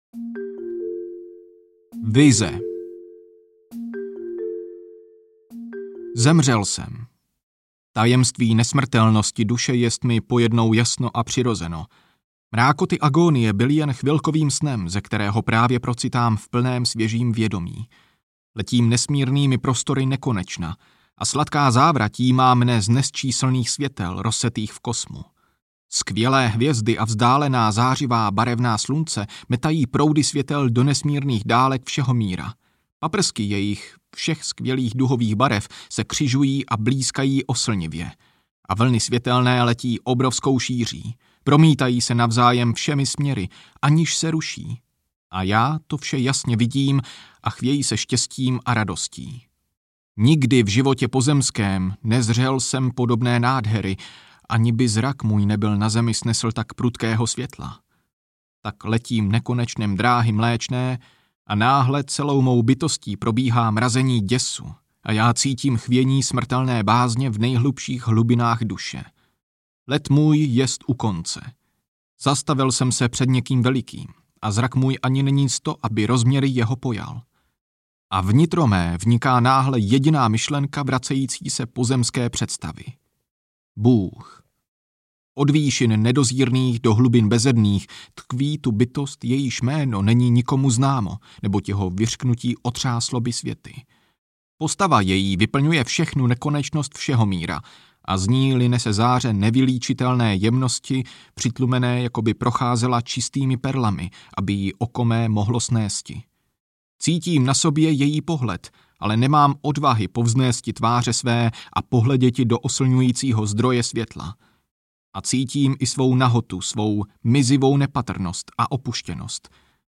Extáze, úžasy a vidění audiokniha
Ukázka z knihy